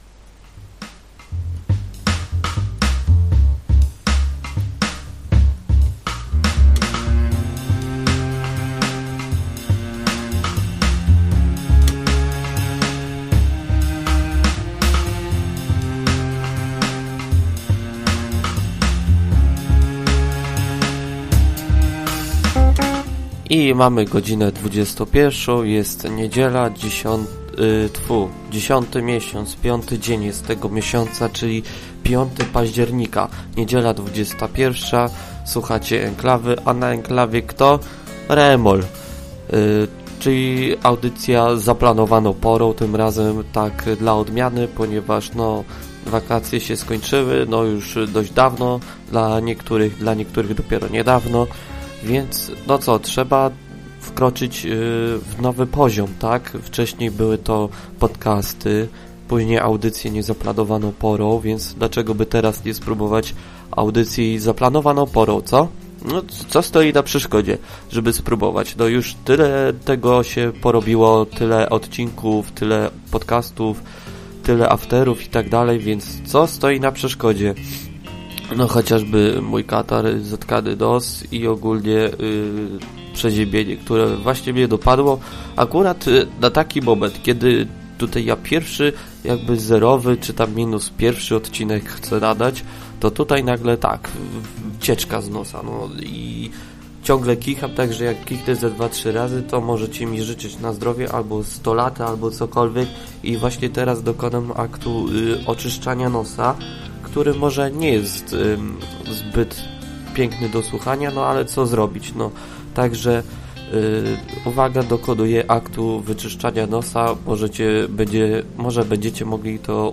A w zasadzie to raczej zerowy, bo i tak nikt na żywo nie słuchał, a i sam prowadzący rozchorowany, więc może to i dobrze. A w audycji o tym, czy lepiej iść na studia, czy do pracy?